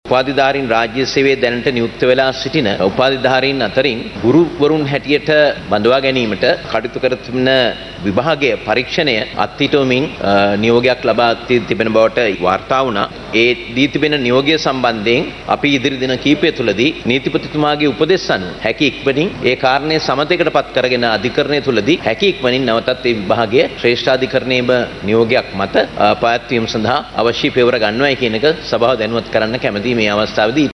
ඊයේ පාර්ලිමේන්තුවේදී අදහස් දක්වමින් අමාත්‍යවරයා මේ බව කියා සිටියා.